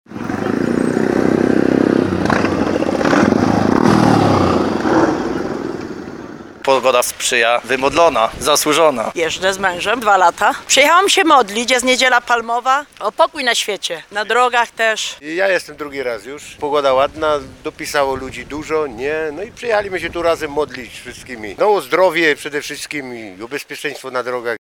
13sonda_motory.mp3